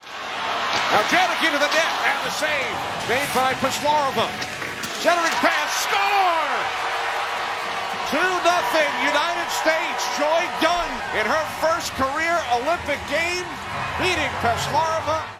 Kenny Albert, USA Network, Team USA women’s hockey opened Olympic play with a 5–1 win over Czechia (Check-EE-uh), and St. Louis native Joy Dunne scored early in the second period.